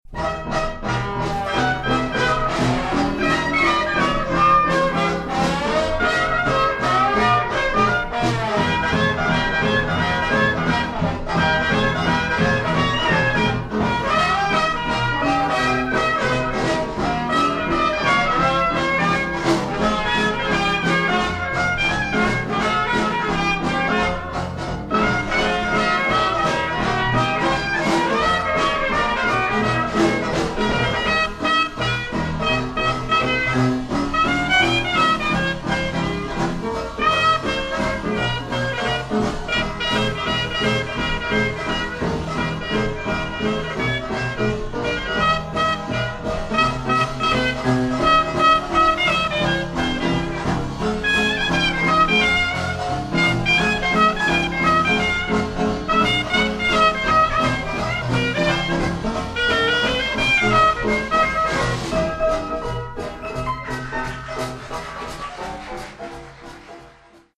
New Orleans Dixieland Jazz